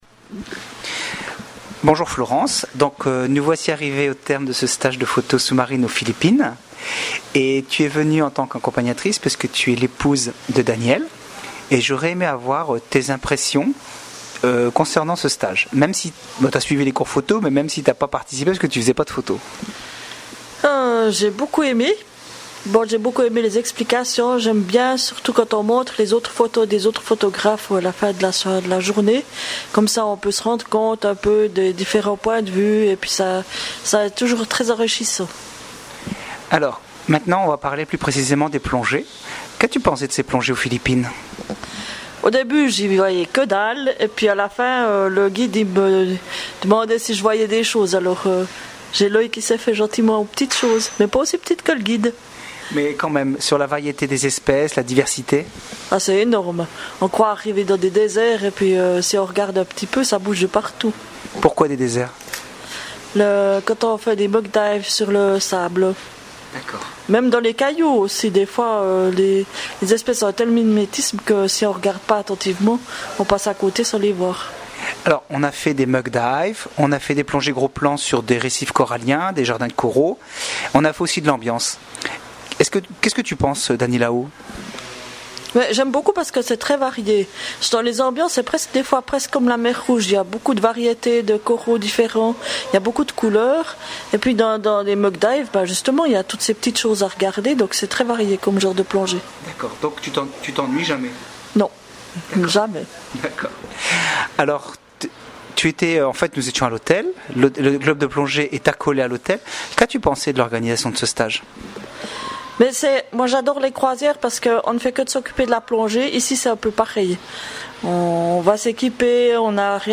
Témoignages écrits et oraux des participants